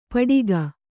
♪ paḍiga